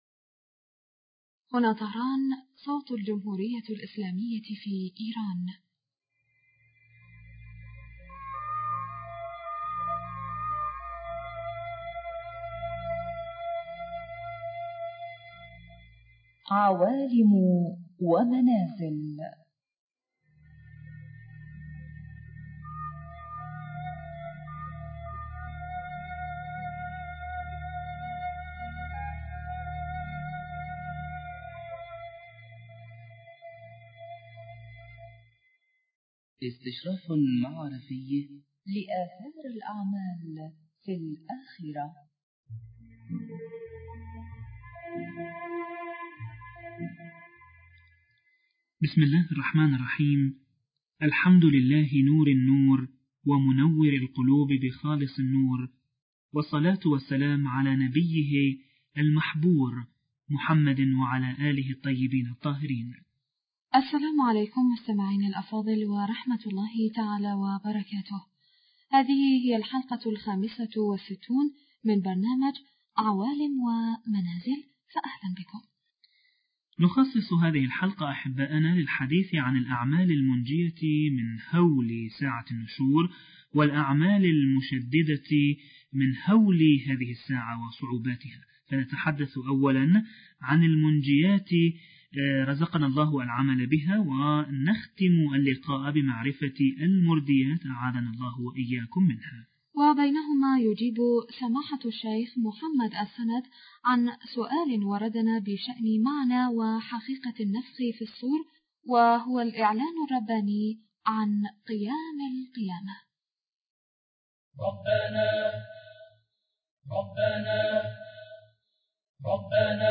رفاق السلام عند النشور حوار